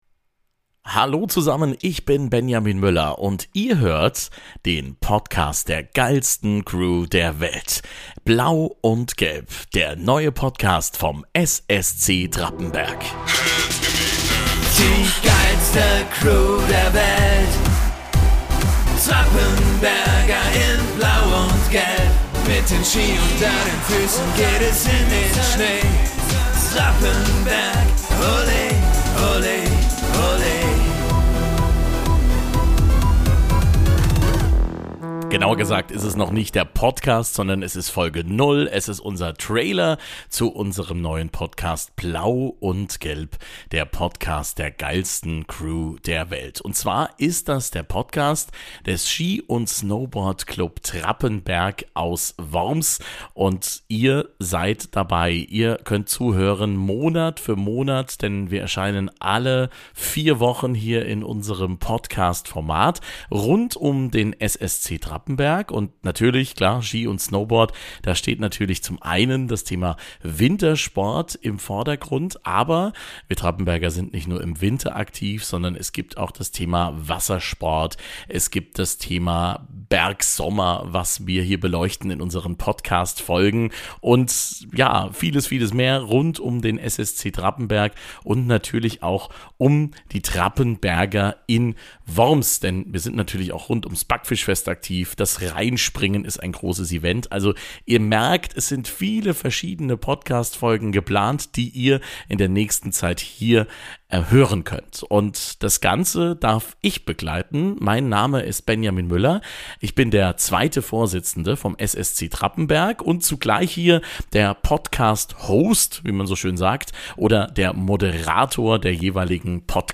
Trailer